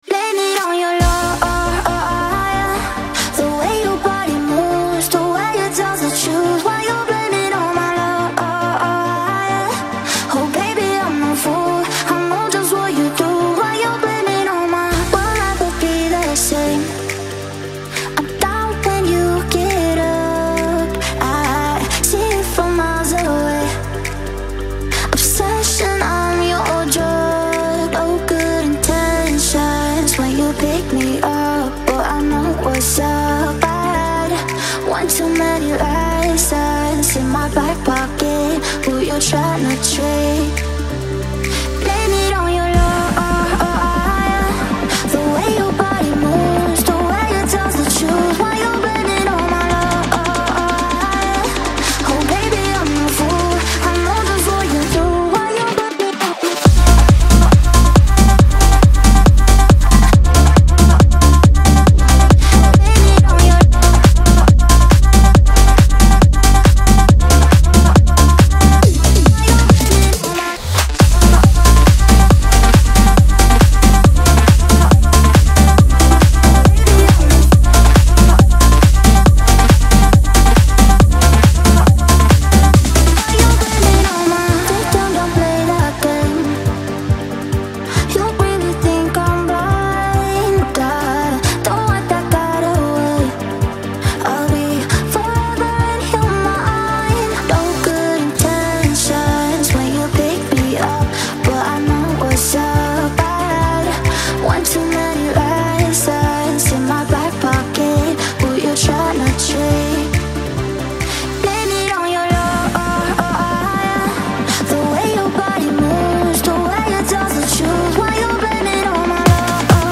• Жанр: Electronic, Dance